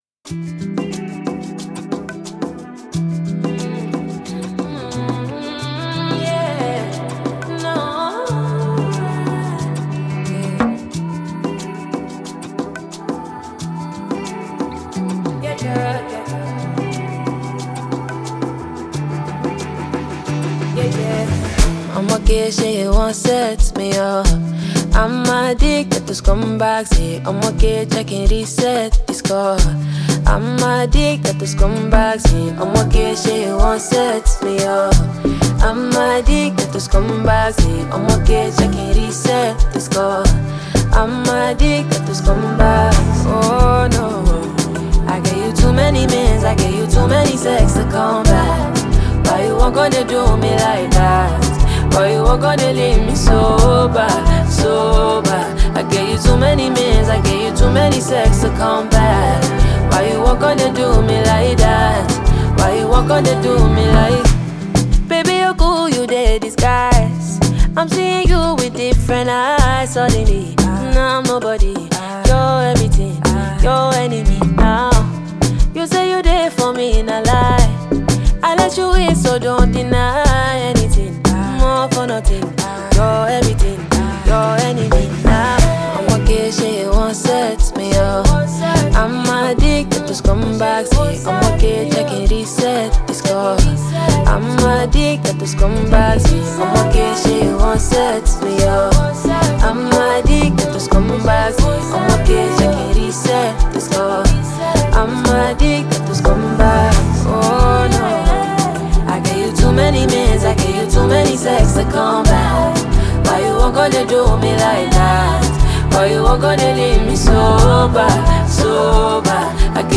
Check out the new tune from Nigerian singer